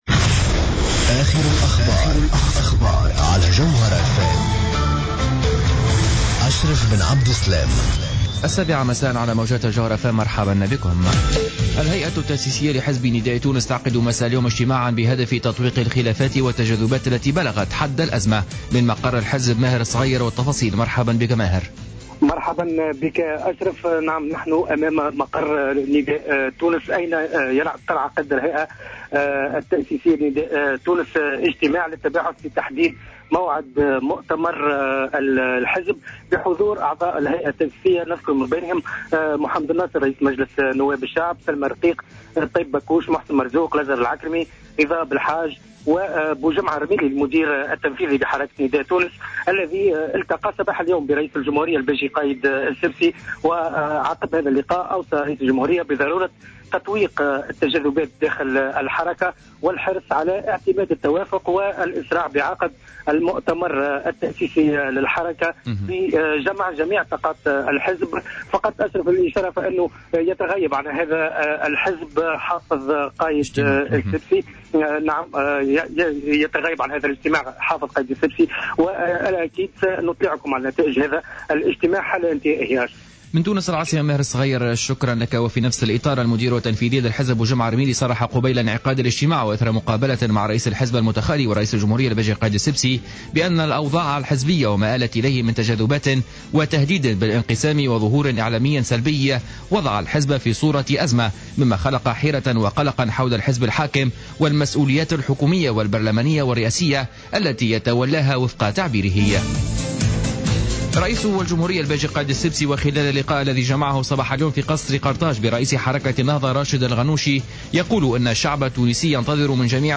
نشرة أخبار السابعة مساء ليوم الثلاثاء 10 مارس 2015